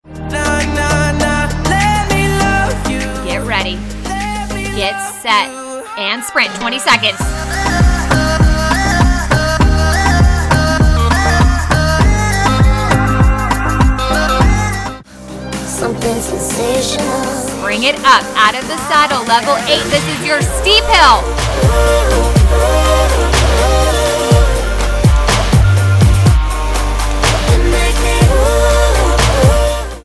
You’ll be in and out of the saddle with standing sprints, rolling hills and hill repeats. Music from artists including Justin Bieber, Backstreet Boys and Ariana Grande.